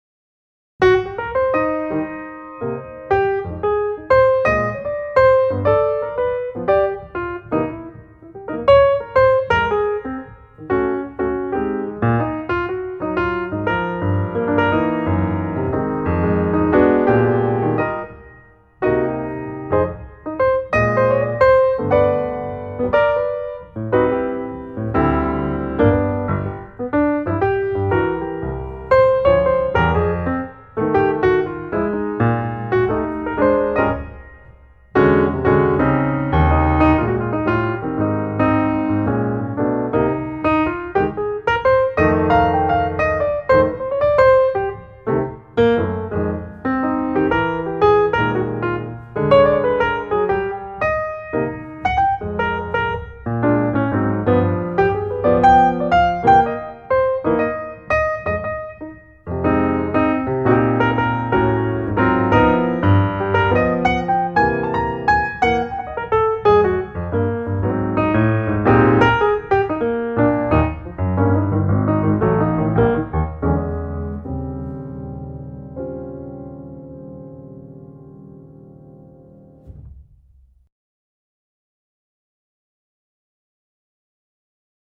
Large Plate Effect Samples
LPlate_Dry.mp3